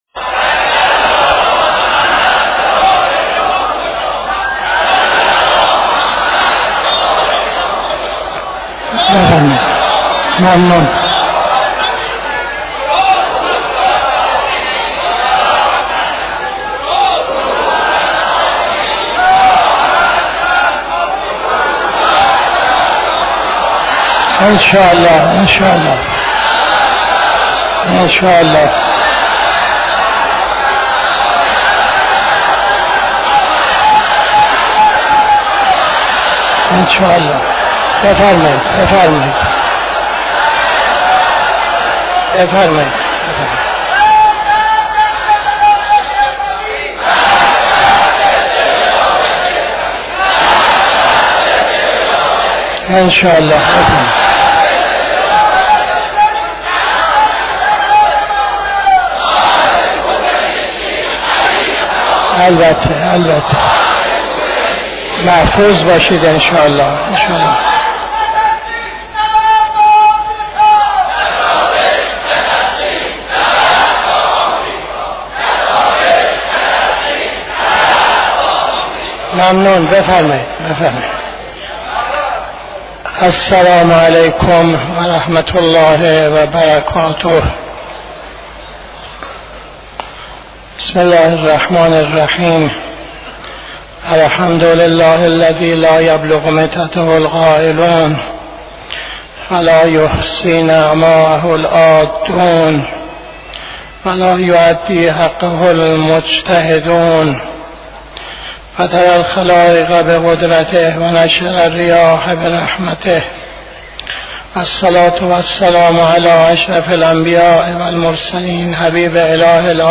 خطبه اول نماز جمعه 24-11-82